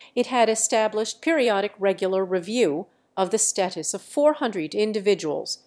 Matcha-TTS - [ICASSP 2024] 🍵 Matcha-TTS: A fast TTS architecture with conditional flow matching
VITS_1.wav